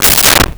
Car Door Closed 03
Car Door Closed 03.wav